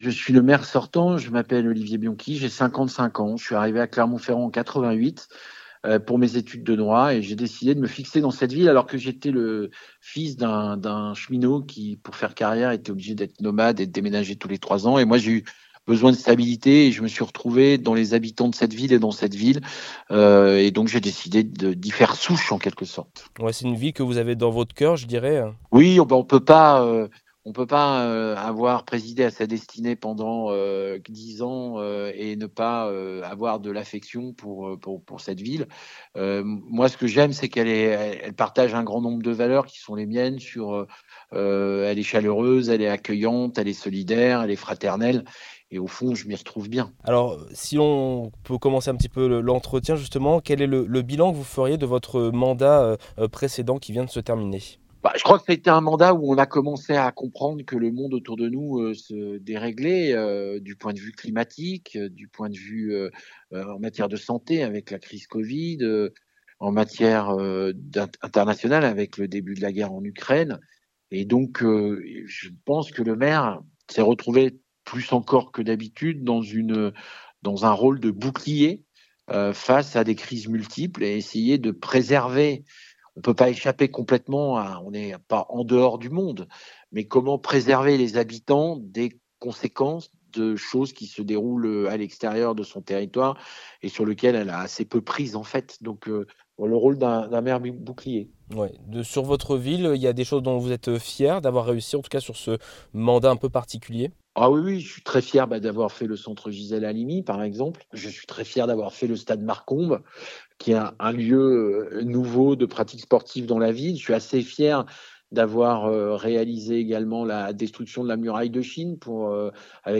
Nous nous sommes entretenu avec les six candidats qui souhaitent briguer la mairie Clermontoise jusqu'en 2033.